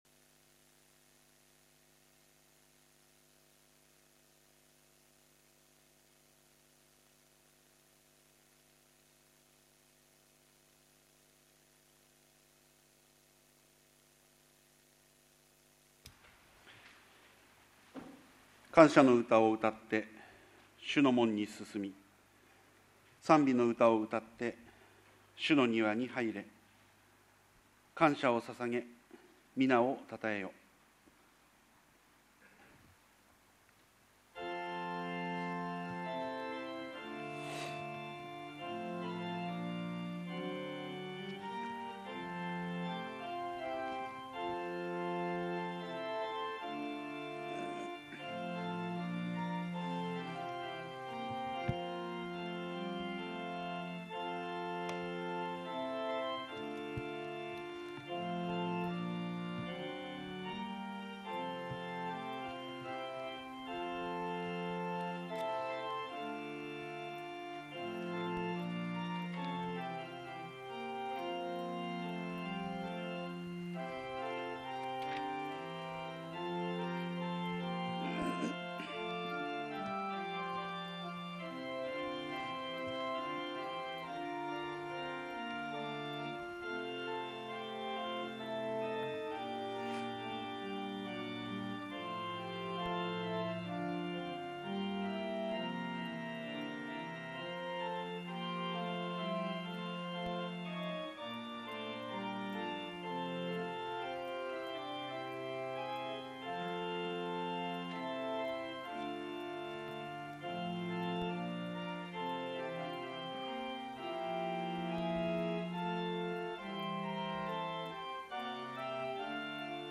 主 日 礼 拝 2020年 6月 21日 10時30分～
********************************************** ＜ 音声のみ＞ 礼拝全体の録音になっています。 マイク音声のため音質良好！